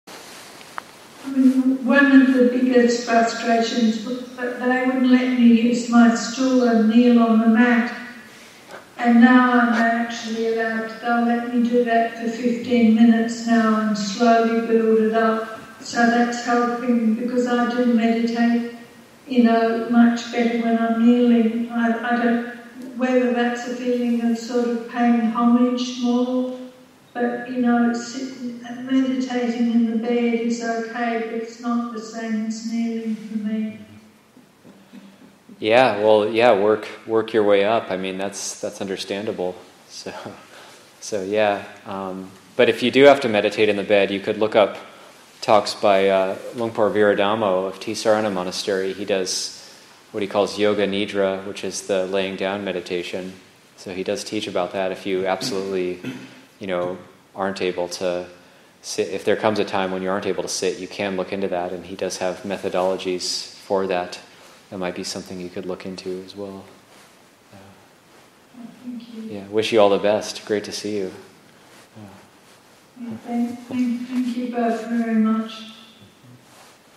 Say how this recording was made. Madison Insight Retreat 2023, Session 2 – Oct. 14, 2023